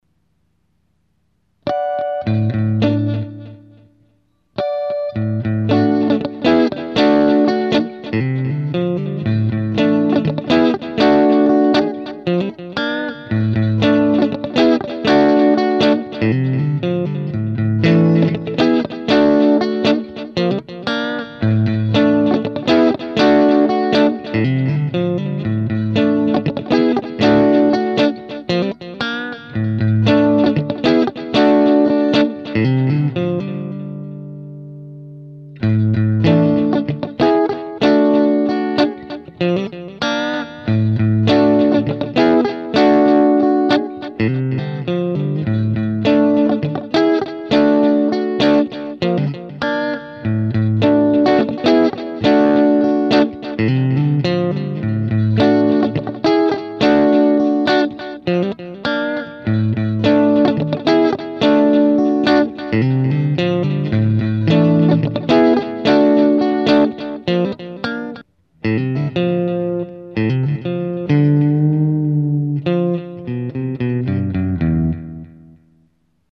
Below are just a few riffs that I recorded so I won't forget.
- Strat through my Roland, vocals to come as soon as I get my mic back.
FunkyStrat.mp3